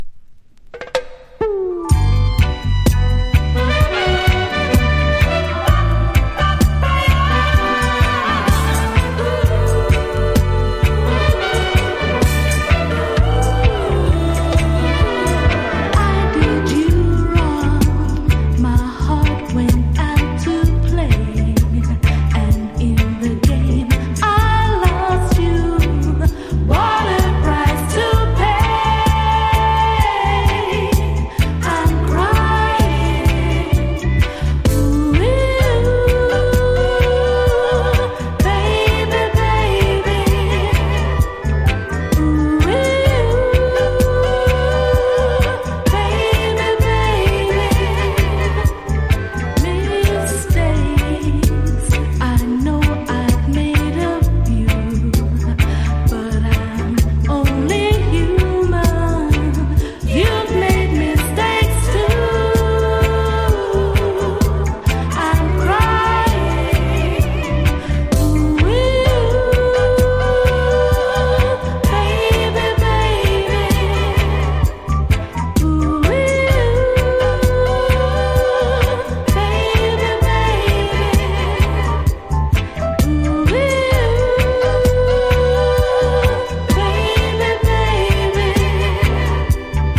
• REGGAE-SKA
極上フィメール・ラヴァーズ!!
# DUB / UK DUB / NEW ROOTS# LOVERS
所によりノイズありますが、リスニング用としては問題く、中古盤として標準的なコンディション。